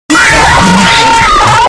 Fnaf 2 Scream Sound Effect Free Download
Fnaf 2 Scream